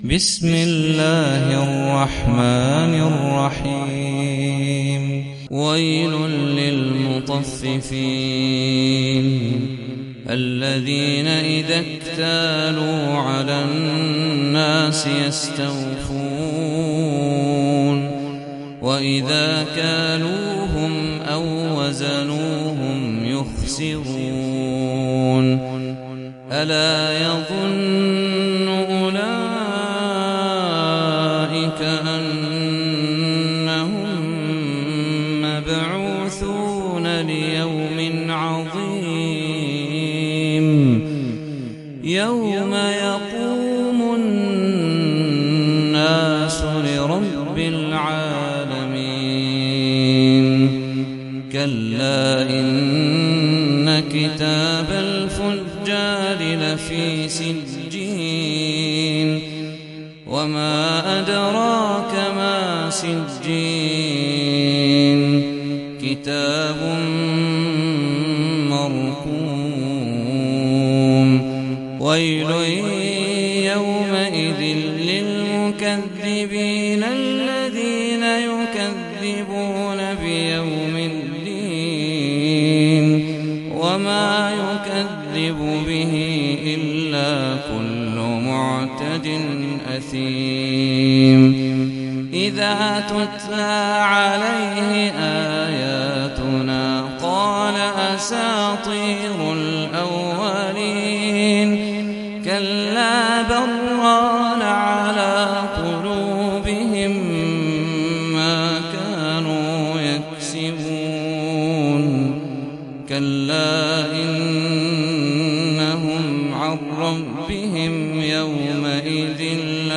سورة المطففين - صلاة التراويح 1446 هـ (برواية حفص عن عاصم
جودة عالية